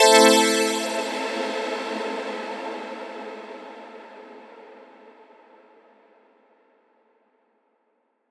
Chords_A_01.wav